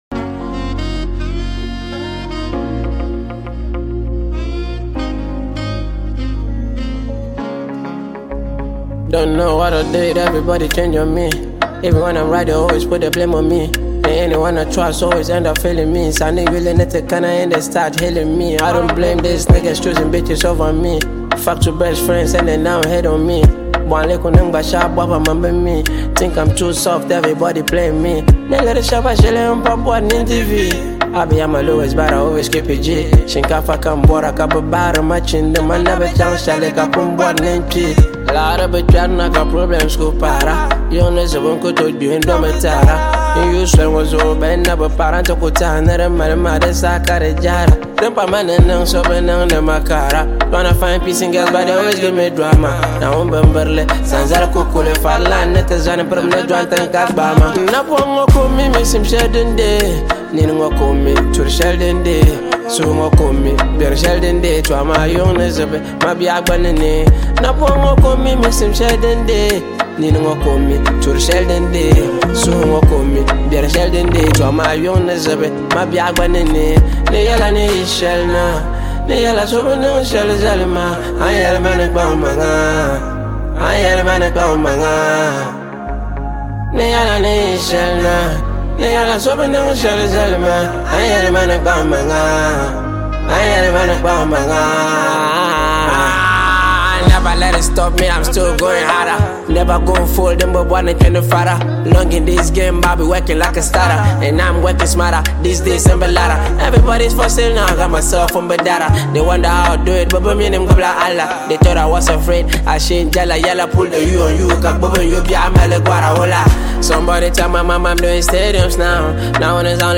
one of Ghana’s most exciting and dynamic rappers